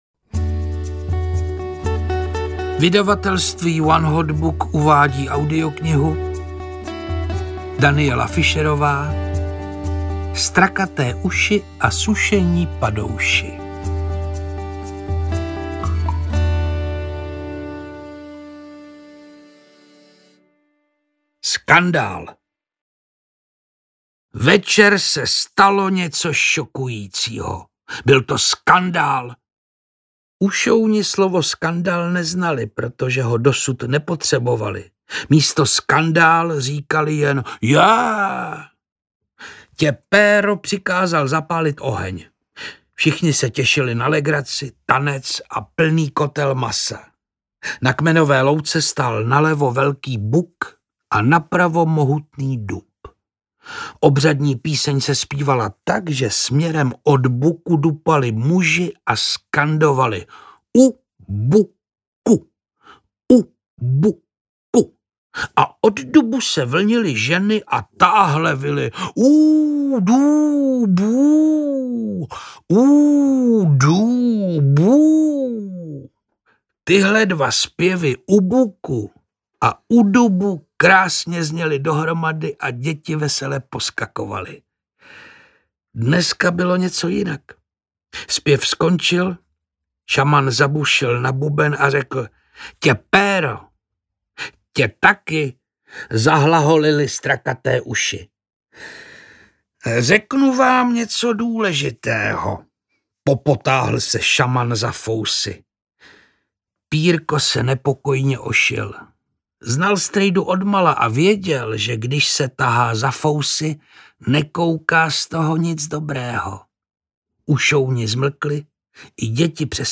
Strakaté uši a sušení padouši audiokniha
Ukázka z knihy
• InterpretPetr Čtvrtníček
strakate-usi-a-suseni-padousi-audiokniha